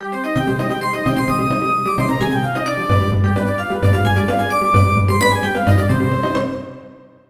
Tonalidad de Do menor. Ejemplo.
tristeza
dramatismo
melodía
severo
sintetizador